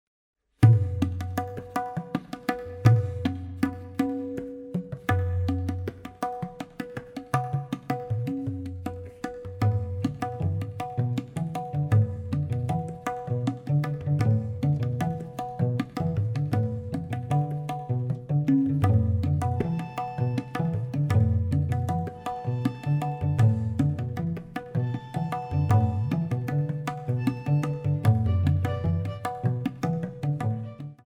viola
cello